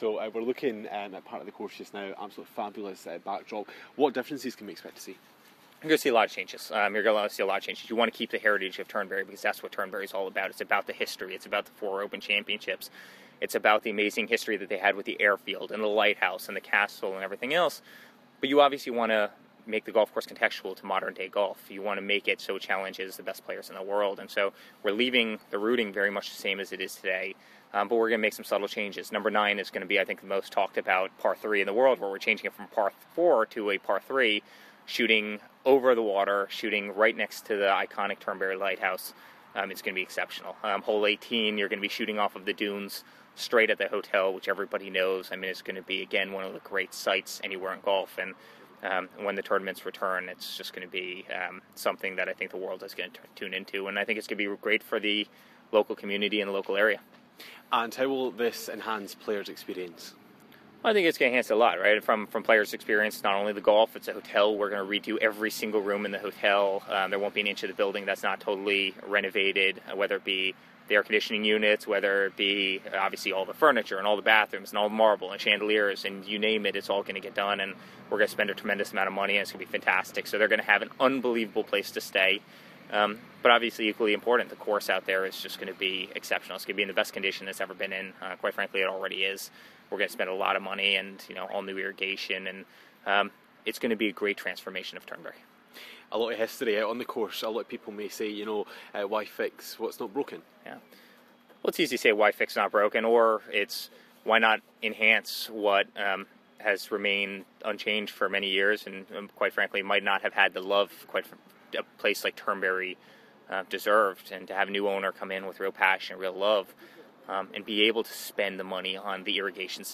caught up with Eric Trump at the course...